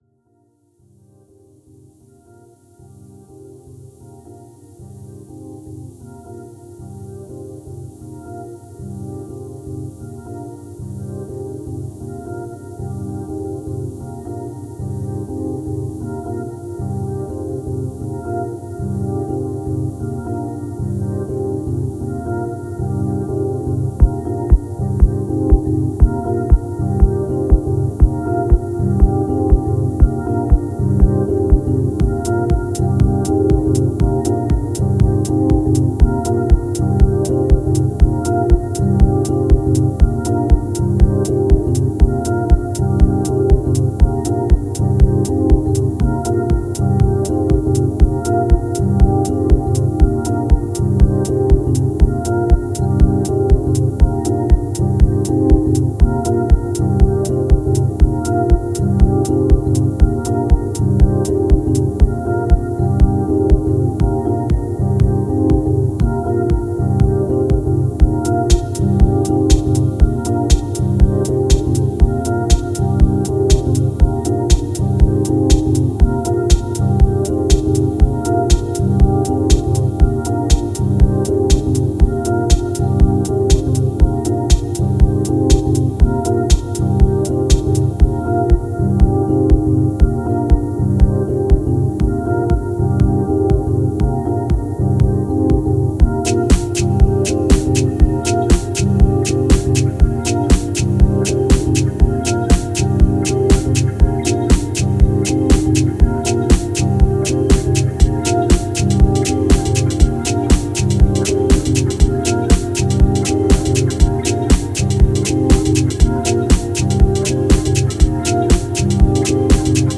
Genre: Dub Techno/Deep Techno.